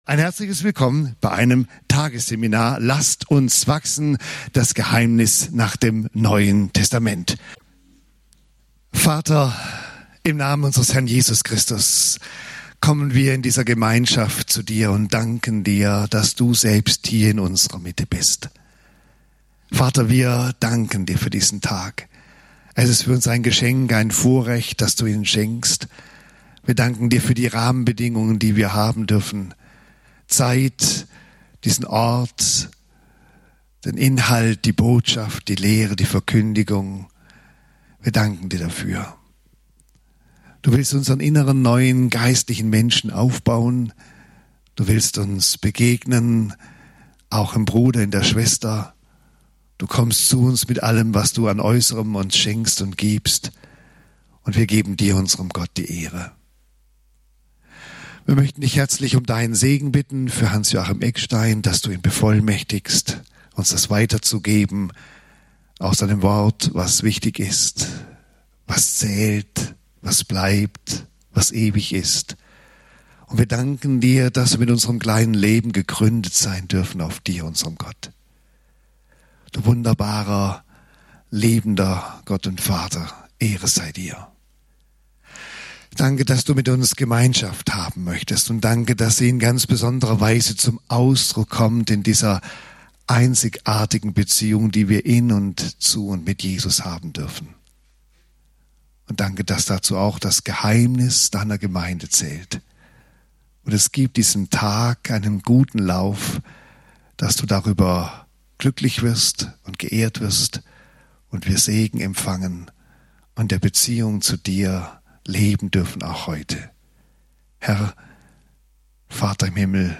Tagesseminar: "Lasset uns wachsen!" - Das Geheimnis der Gemeinde nach dem Neuen Testament (Eph. 4, 15+16) - Teil 1